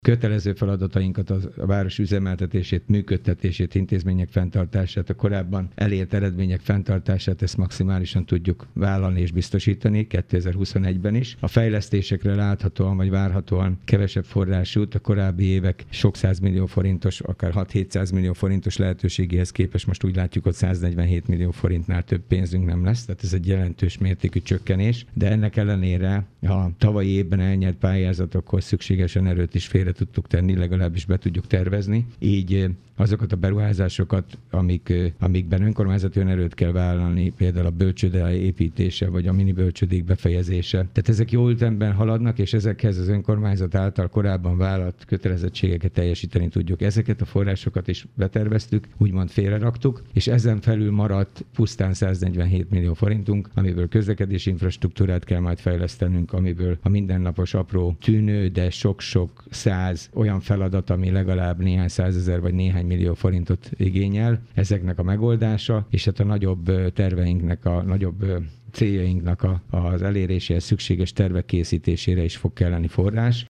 A kötelező feladatok ellátásával nem lesz gond, azonban a fejlesztéseket vissza kell fogni Dabason - ez látszik a készülő költségvetésből. Kőszegi Zoltán polgármester azt mondta, az önkormányzati önerőt igénylő beruházások is megvalósulnak, mert azokra korábban félretették a pénzt.